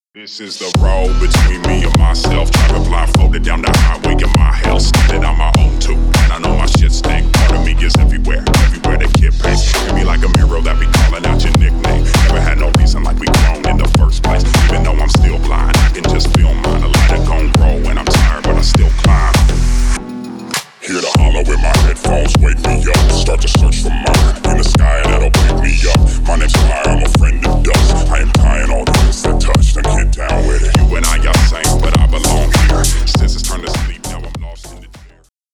Ремикс # Рэп и Хип Хоп
громкие